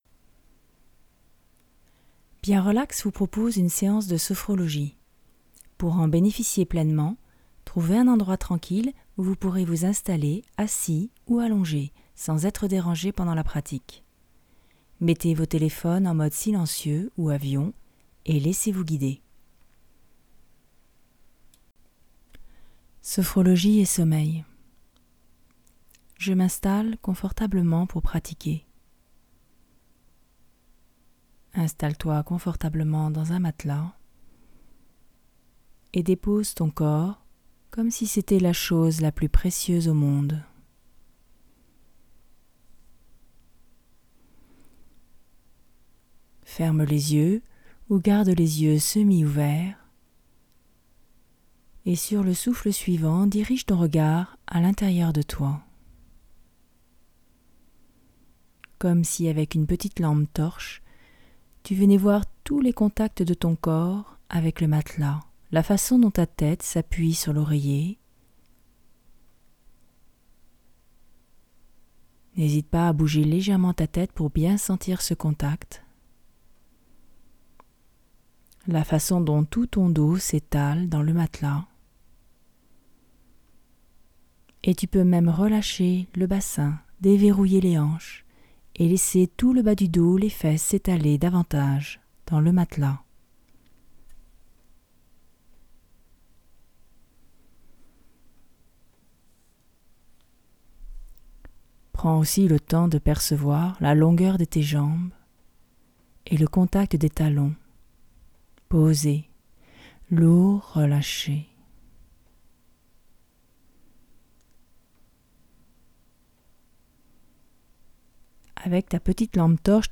Genre : Sophrologie